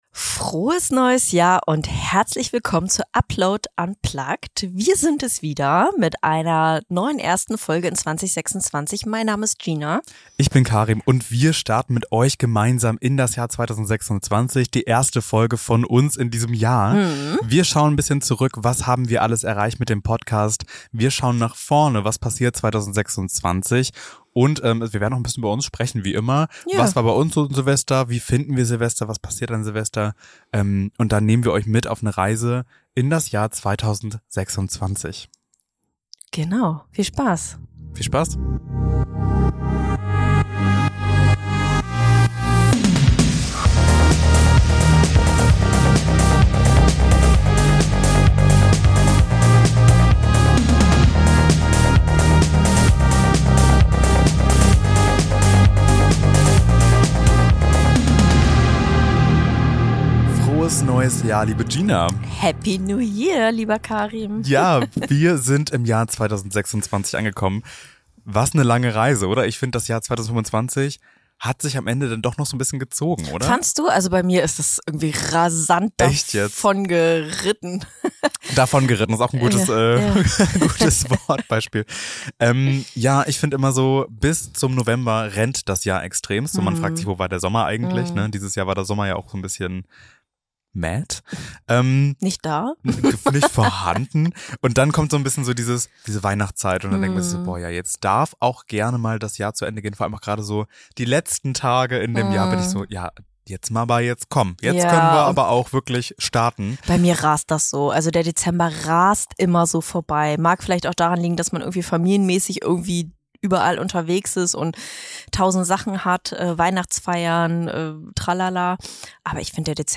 Wir starten ganz entspannt ins neue Jahr: nur wir zwei Hosts, ohne Gast, dafür mit guter Laune, kleinen Rückblicken und ein bisschen Zukunftsplanung.